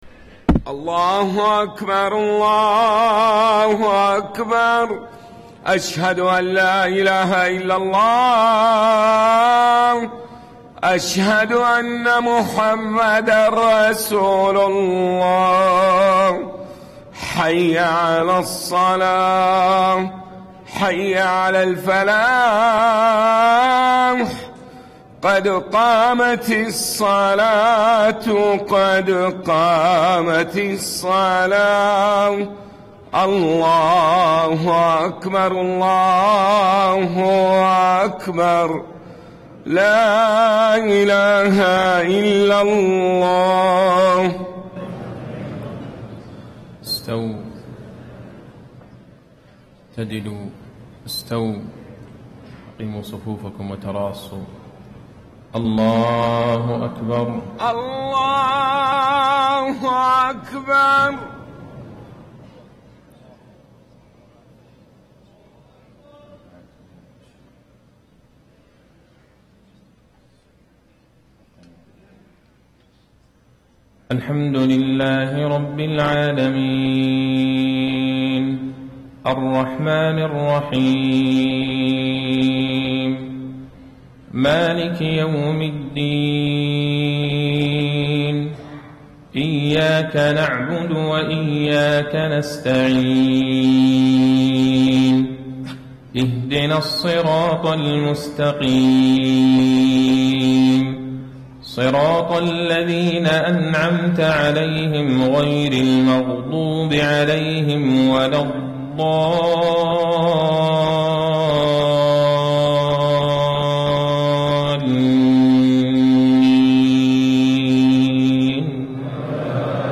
صلاة المغرب 4 - 7 - 1435هـ سورتي القدر و الزلزلة > 1435 🕌 > الفروض - تلاوات الحرمين